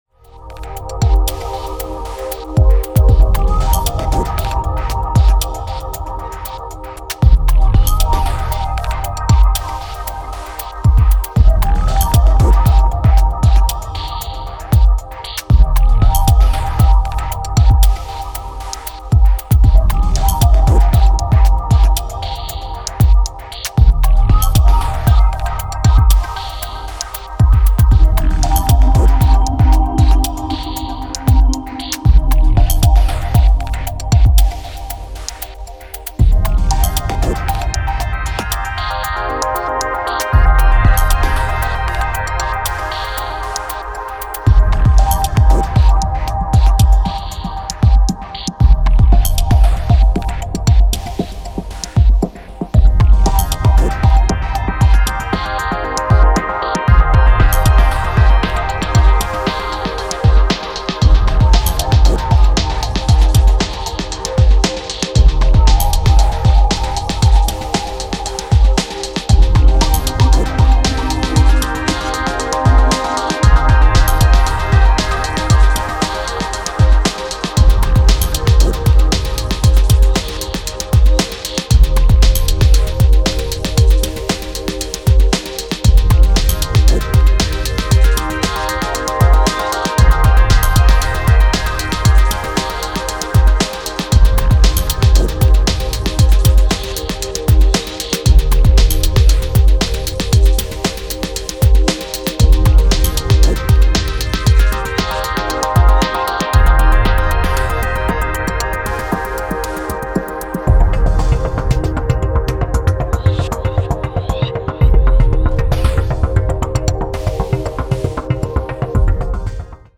Downtempo Dub Techno